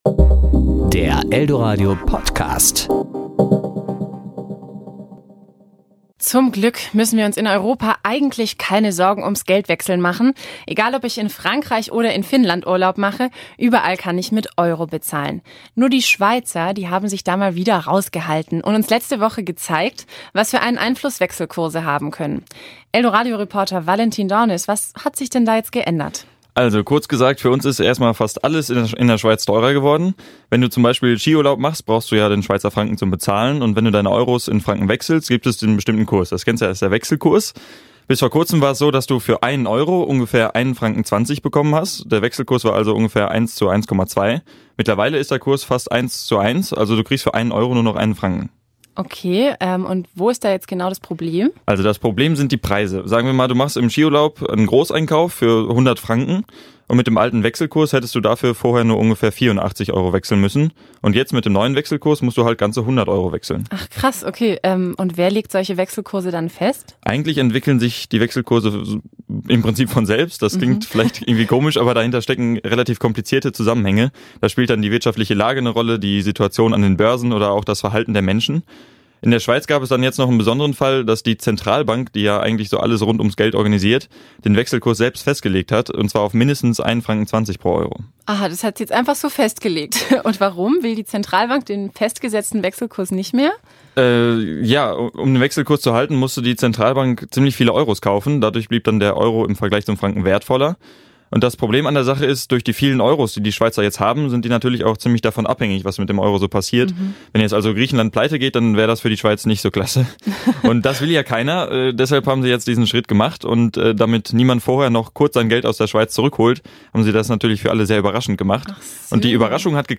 Serie: Kollegengespräch  Sendung: Toaster